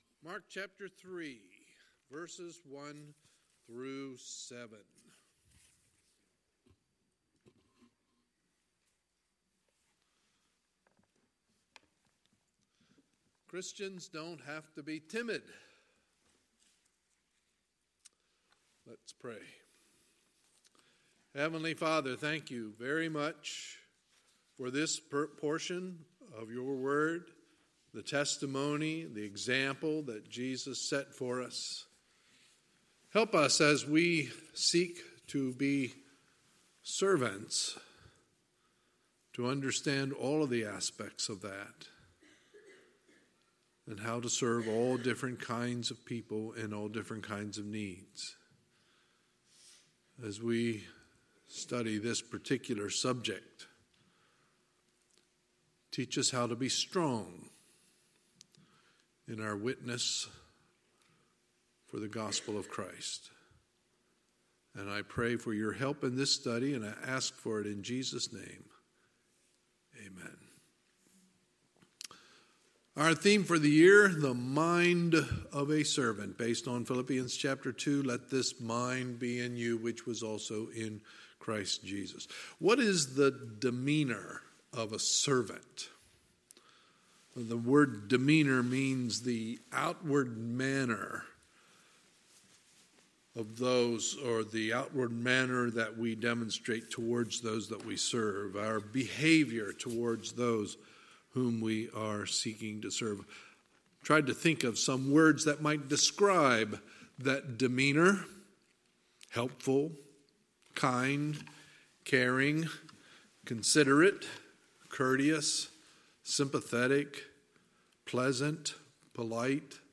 Sunday, March 3, 2019 – Sunday Morning Service